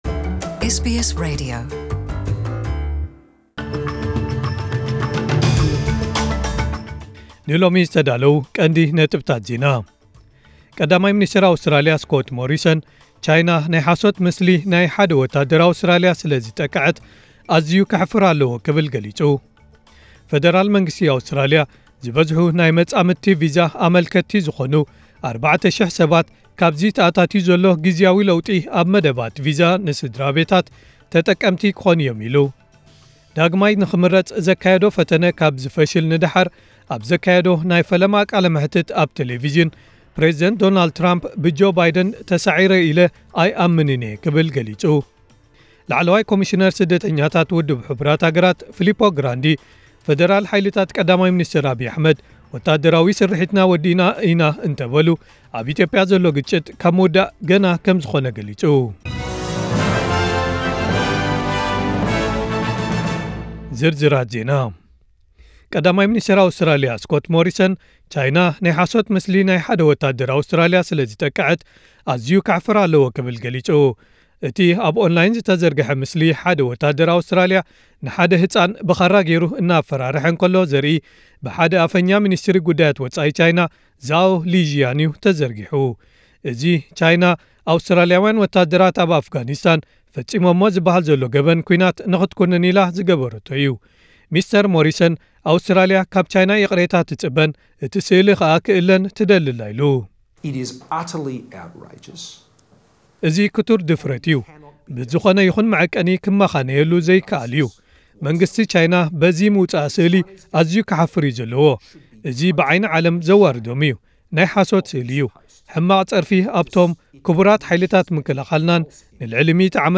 ዕለታዊ ዜና ኤስቢኤስ ትግርኛ (30/11/2020)